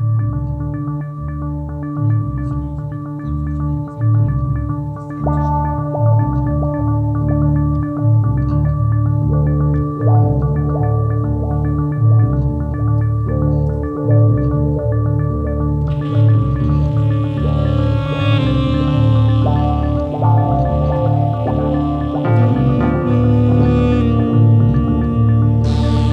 Featured in Electro RIngtones